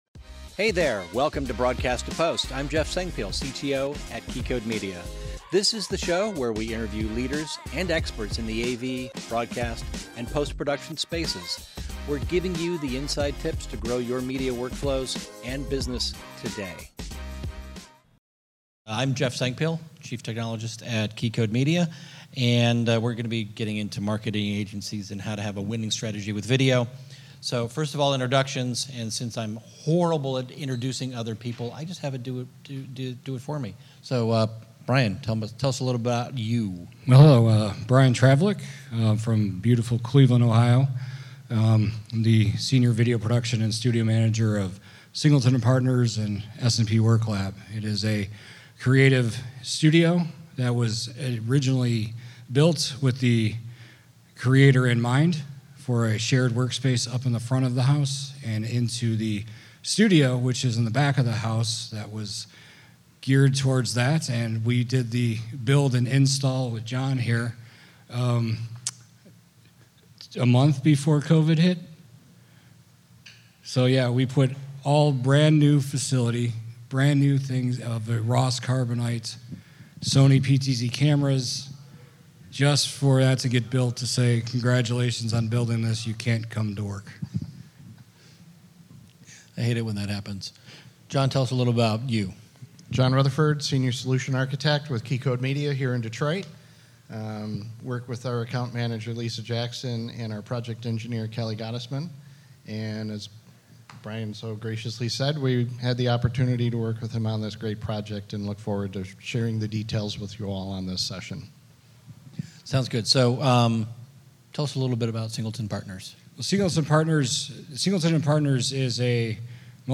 This is a live recording from our PostNAB Detroit event.